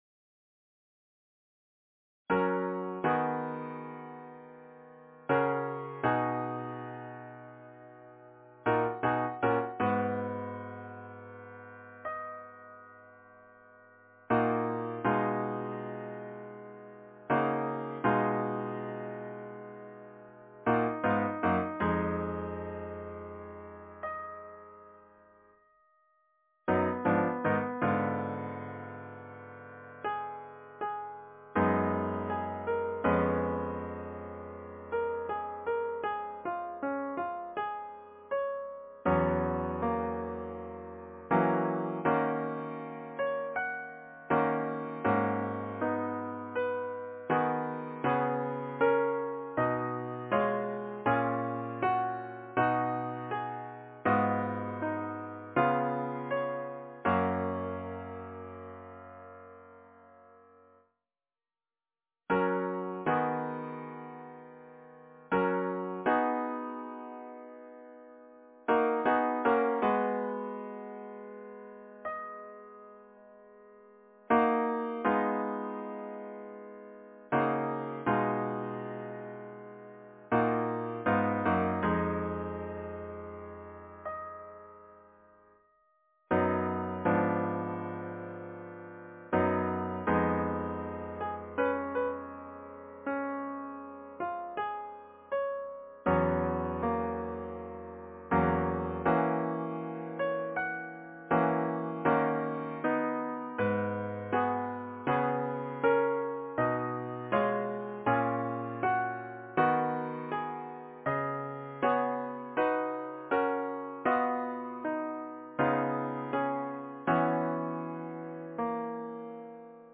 Number of voices: 5vv Voicing: SATB, with Choral soprano solo, SSATB, or SAATB (originally)
Origin Genre: Secular, Partsong